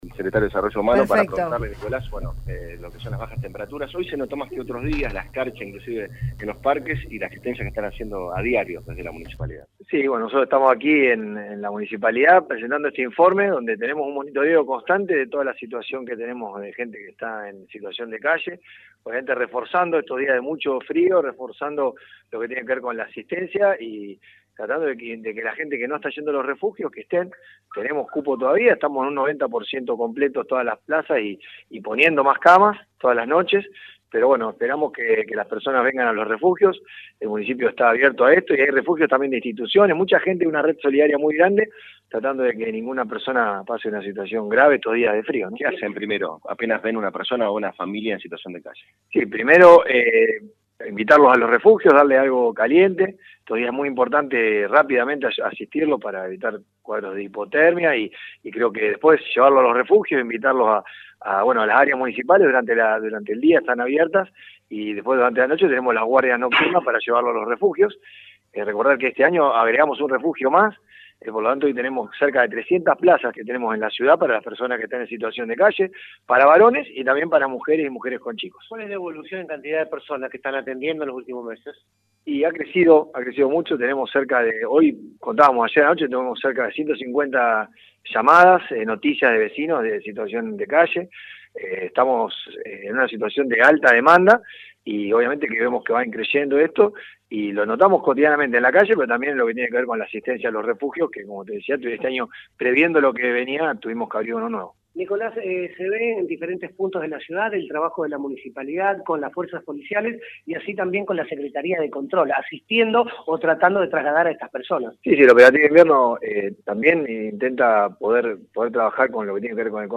Nicolas Gianelloni, secretario de Desarrollo Humano y Hábitat de la Municipalidad de Rosario habló en el programa Digamos Todo y se refirió al operativo invierno que vienen realizando en estos días donde ha recrudecido el frío en las calles y desde el Municipio buscan que ninguna persona en situación de calle se quede sin ir a un refugio.